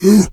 pgs/Assets/Audio/Animal_Impersonations/bear_roar_soft_04.wav at master
bear_roar_soft_04.wav